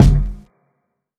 Kicks
livesh_kickr.wav